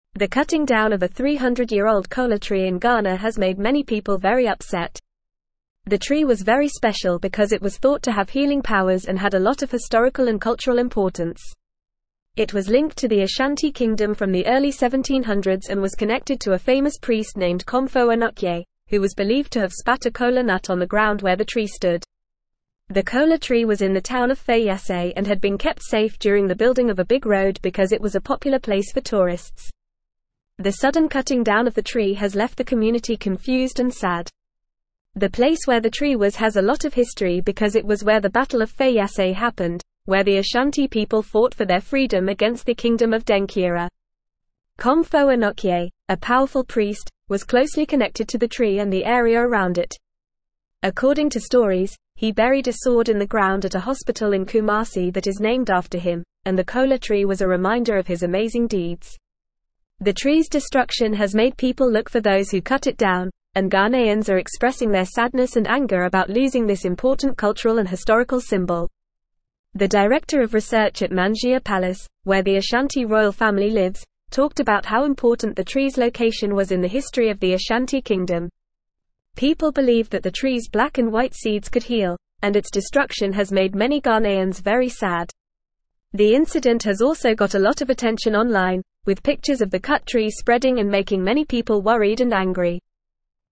Fast
English-Newsroom-Upper-Intermediate-FAST-Reading-Manhunt-in-Ghana-for-Culprits-Behind-Ancient-Trees-Destruction.mp3